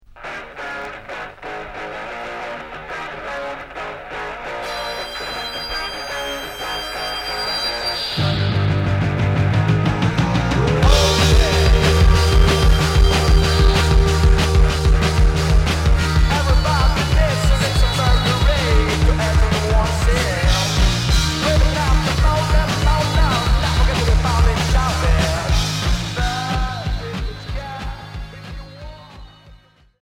Punk rock Premier 45t retour à l'accueil